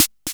aGMACHINE_SNR.wav